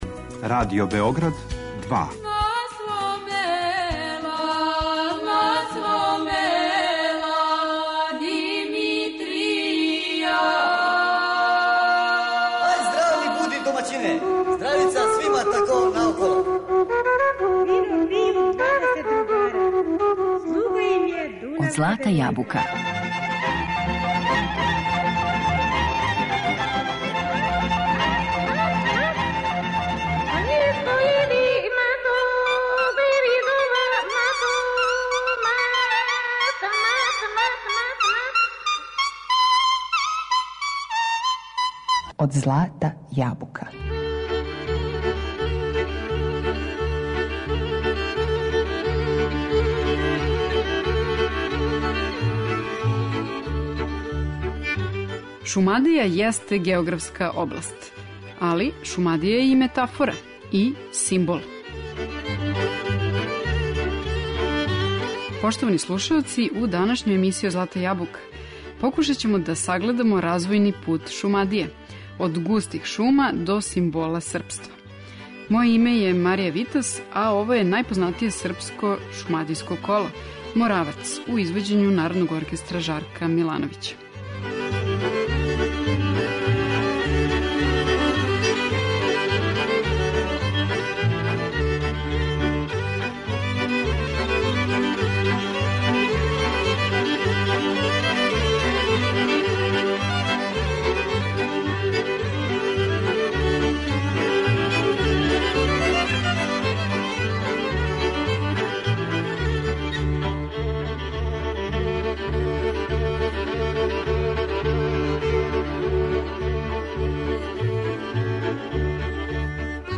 Уз одговарајућу песму и музику за игру са простора Централне Србије, сагледавамо развојни пут Шумадије - од густих шума до симбола српства.